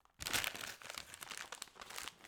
unwrap-min.wav